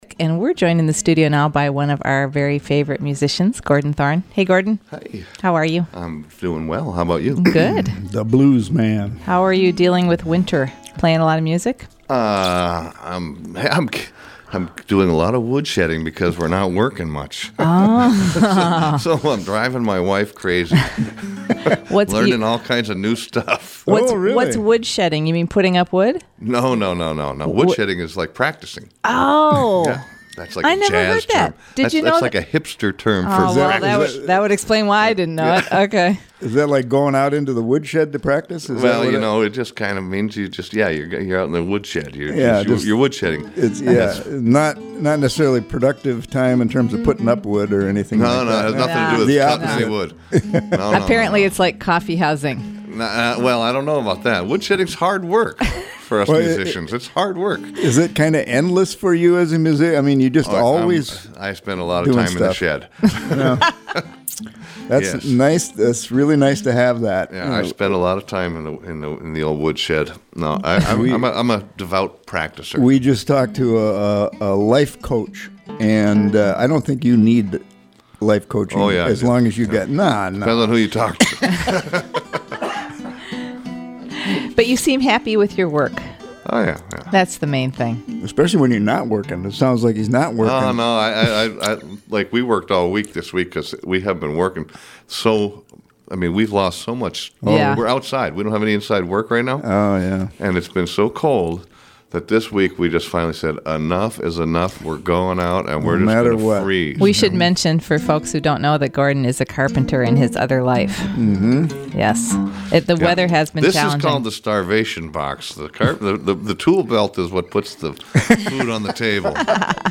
fun conversation and wonderful blues guitar